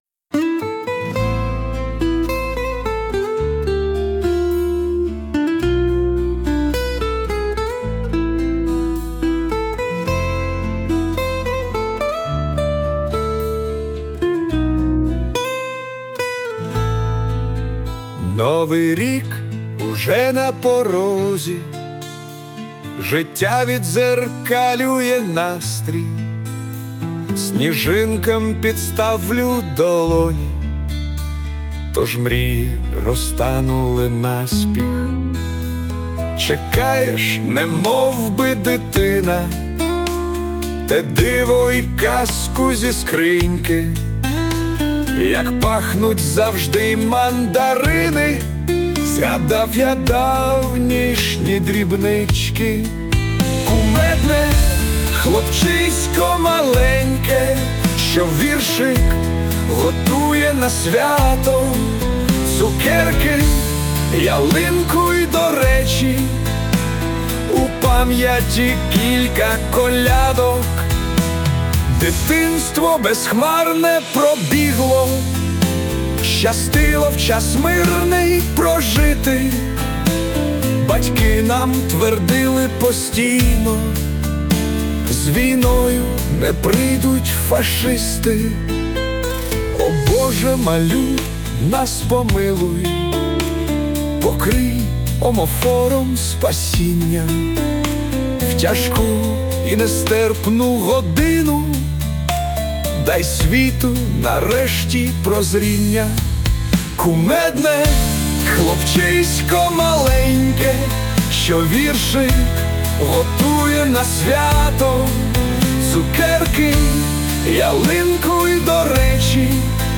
Музична композиція створена за допомогою ШІ
СТИЛЬОВІ ЖАНРИ: Ліричний